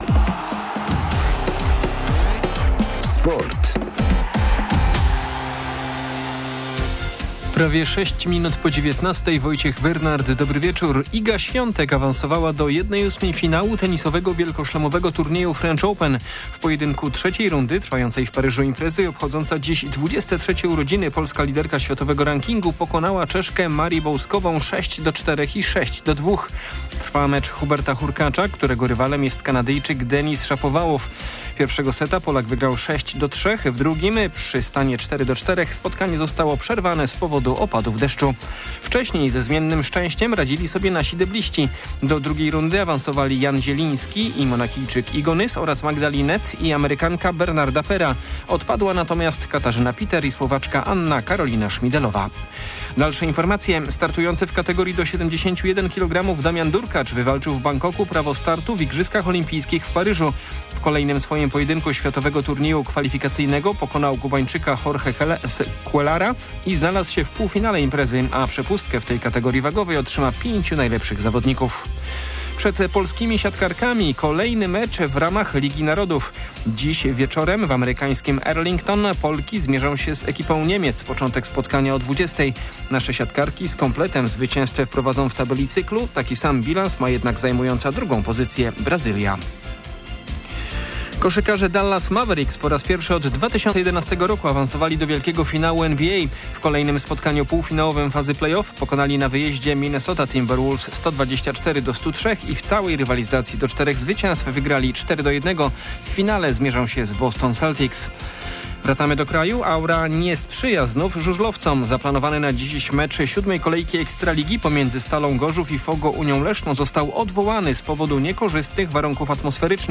31.05.2024 SERWIS SPORTOWY GODZ. 19:05